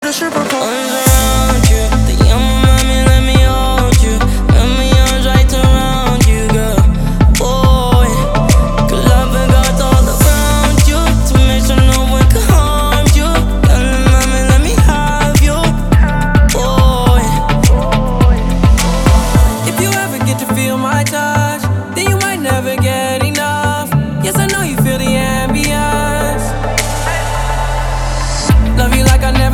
• Качество: 320, Stereo
ритмичные
мужской вокал
Хип-хоп